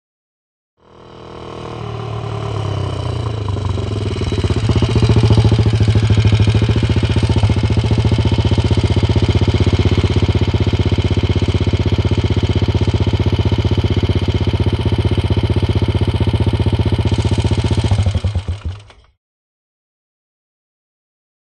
Motorcycle; In / Stop / Off; B.s.a. Single Up To Mic. Idling And Switch Off.